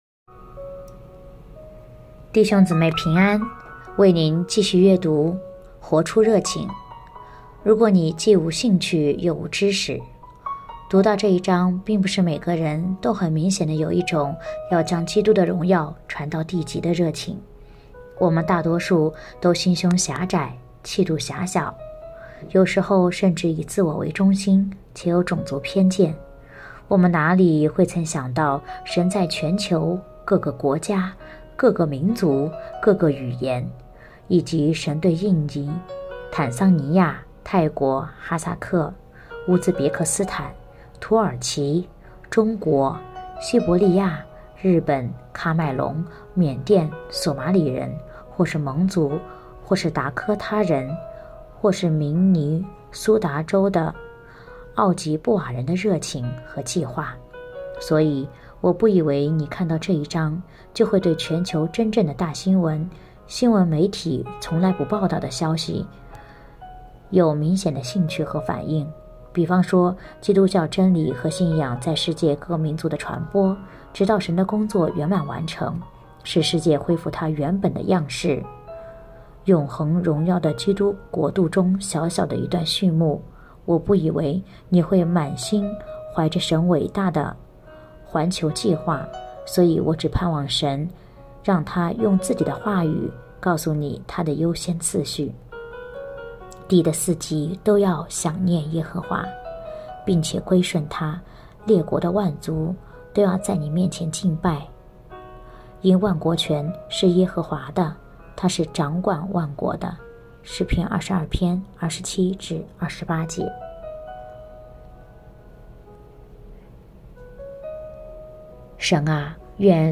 2024年8月29日 “伴你读书”，正在为您朗读：《活出热情》 欢迎点击下方音频聆听朗读内容 音频 https